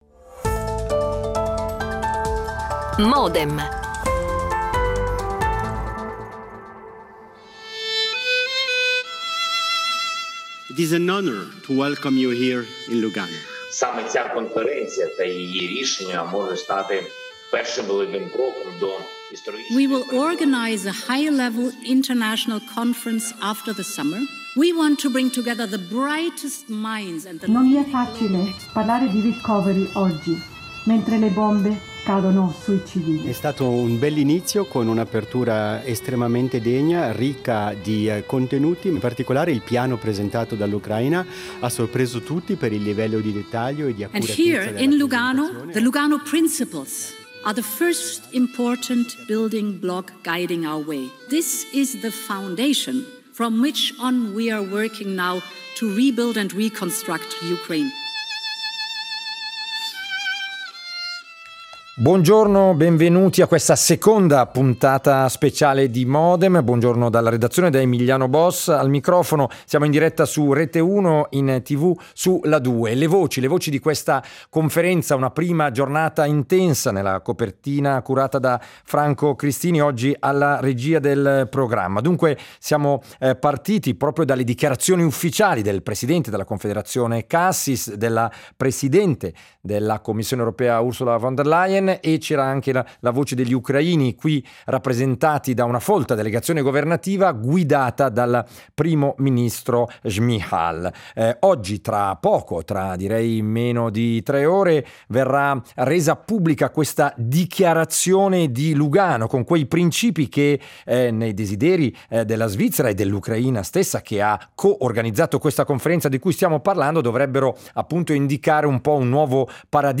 Ospiti in studio e in diretta telefonica, collegamenti con gli inviati alla Conferenza e interviste.